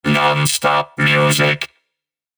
• Eenstemmige Robo-Voice-jingle op 1 toonhoogte
Zonder Soundeffects
RoboVoice - Non Stop Music.mp3